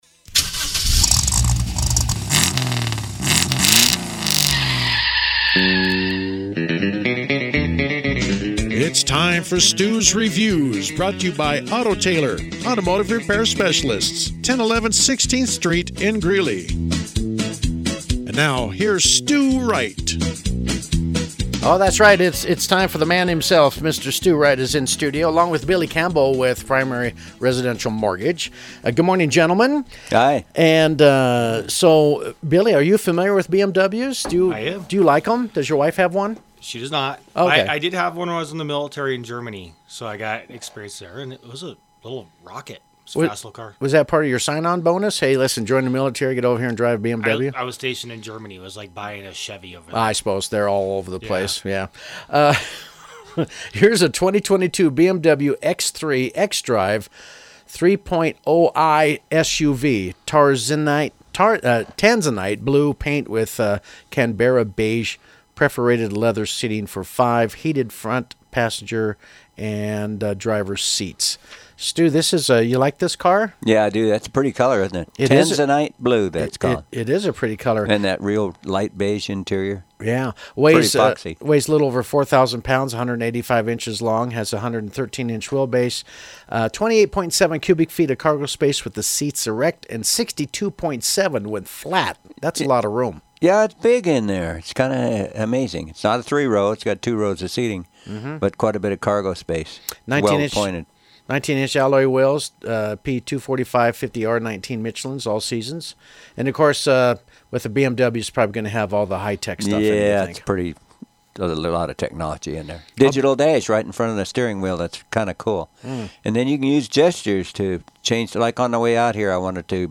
joined me in an analysis of the BMW X3: